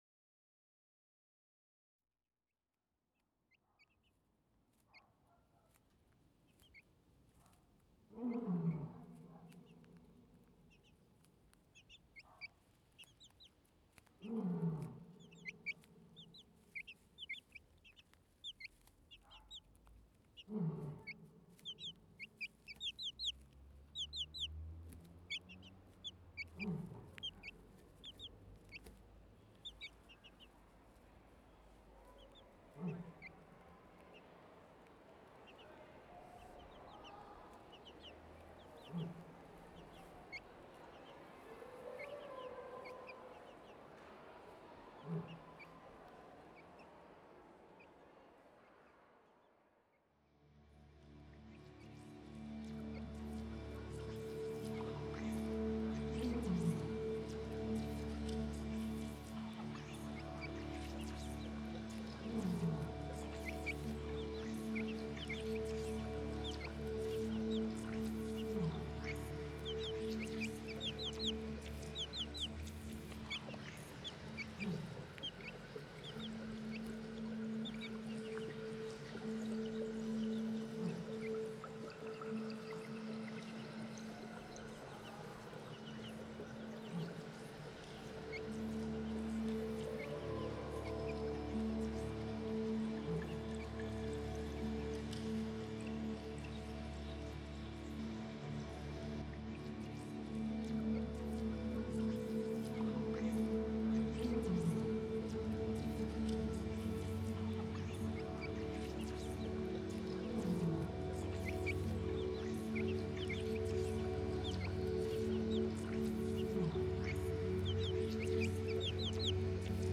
A continuous piece of audio co-created from layers made out of students work
This is a demo of the piece running for a few minutes. Made with Sonic Pi.
soundsofcollapsedemo.wav